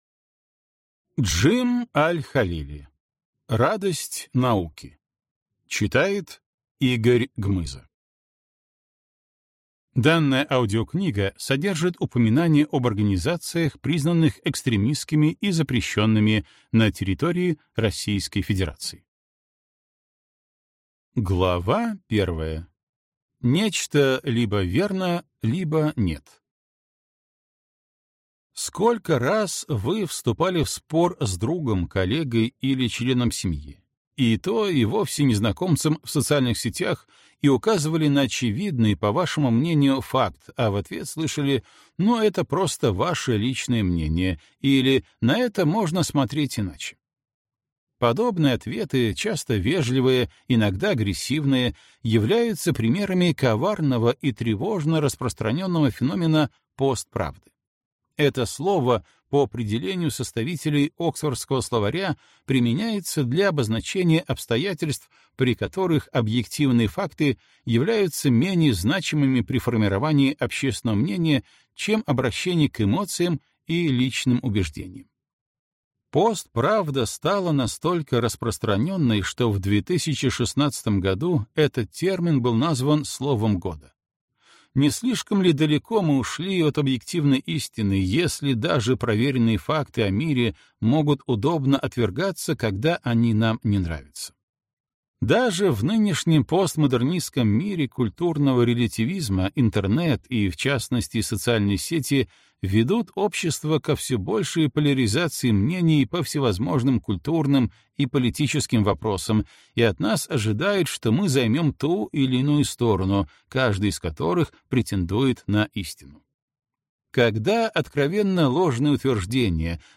Аудиокнига Радость науки. Важнейшие основы рационального мышления | Библиотека аудиокниг